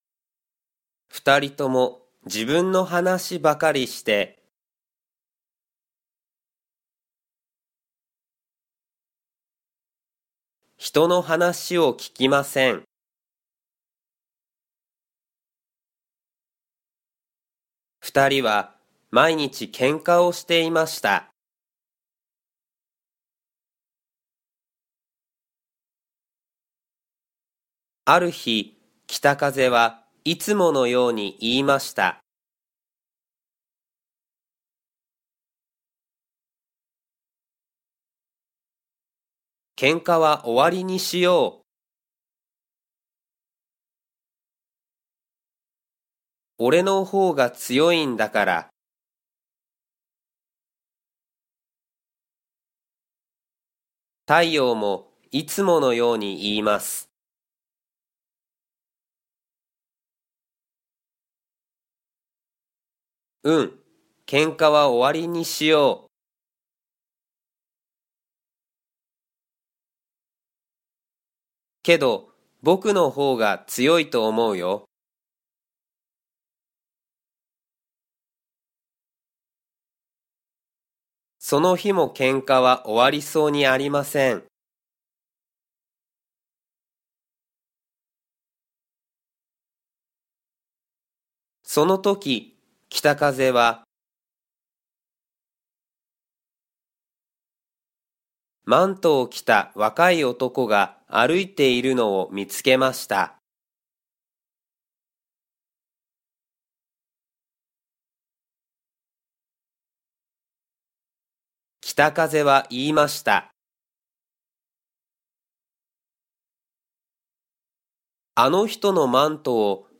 Japanese Graded Readers: Fairy Tales and Short Stories with Read-aloud Method
Slow Speed
Natural Speed